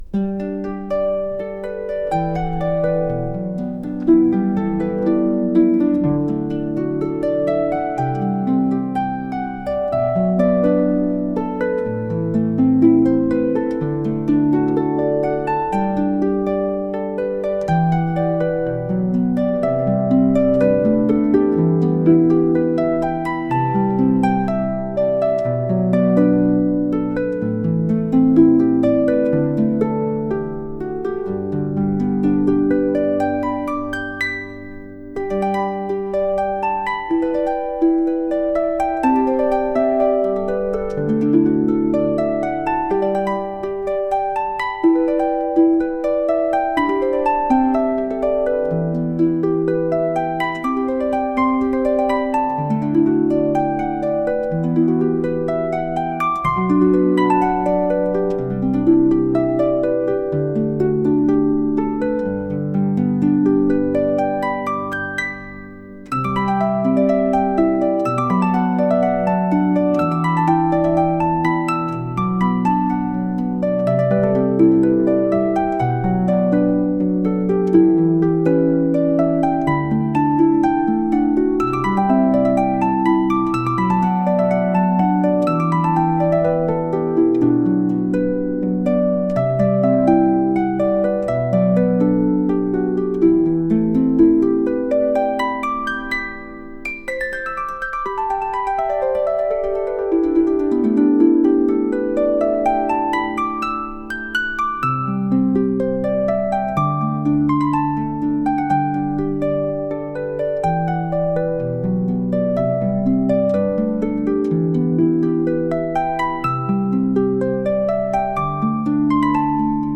冬を感じるようなハープの曲です 音楽素材（MP3）ファイルのダウンロード、ご利用の前に必ず下記項目をご確認ください。